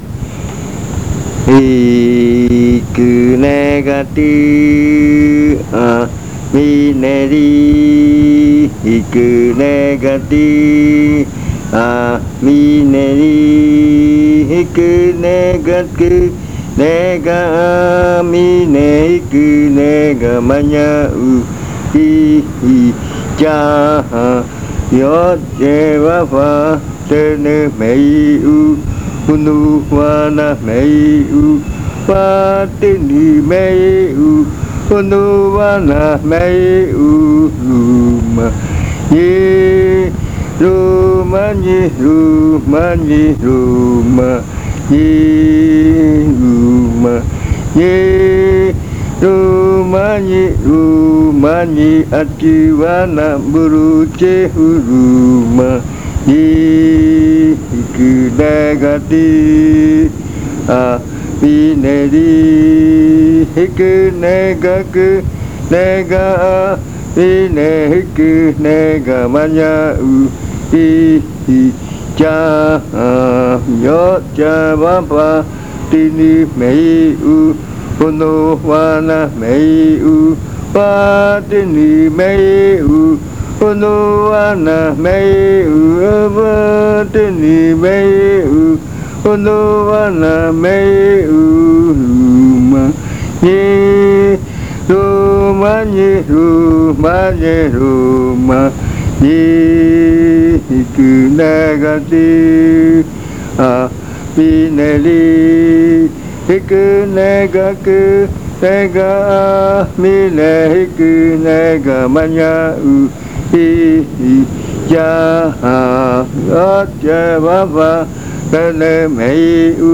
Leticia, Amazonas
Esta canción es de la etnia bora, sin embargo, el cantor murui que entiende la lengua bora canta esta canción maravillosa a su manera de cantar.
This chant is of the Bora ethnic group, however, the Murui singer, who understands the Bora language, sings this wonderful chant in his own way of singing.
This chant is part of the collection of chants from the Yuakɨ Murui-Muina (fruit ritual) of the Murui people